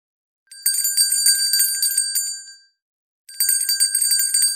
Dashboard bell
bell.wav